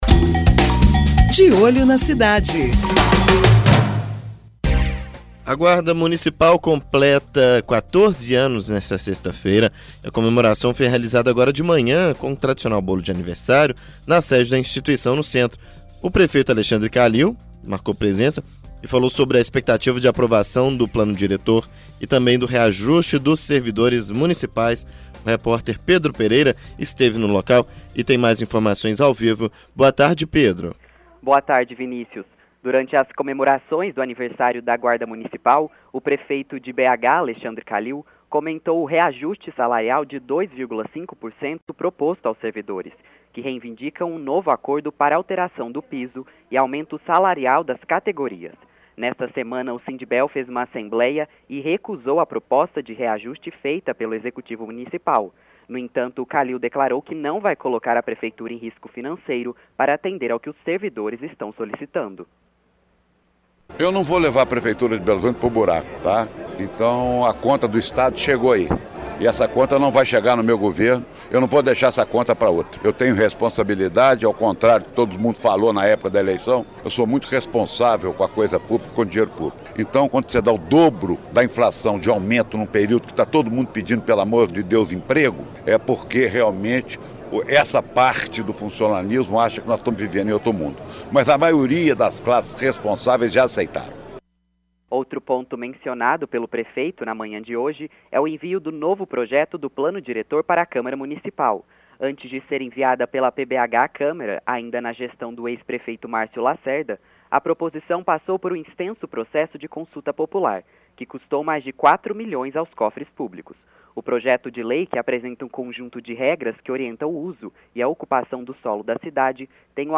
De Olho na Cidade: Guarda Municipal de Belo Horizonte completa 14 anos nesta sexta. A comemoração foi realizada com um tradicional bolo de aniversário na sede da instituição no centro. O prefeito Alexandre Kalil marcou presença e falou sobre a expectativa de aprovação do plano diretor e o reajuste dos servidores municipais.